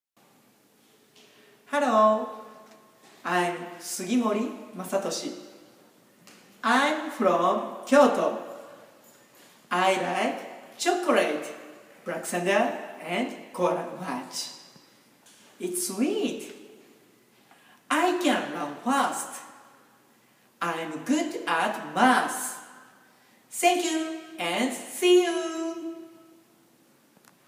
６年生 英語で自己紹介「This is me.」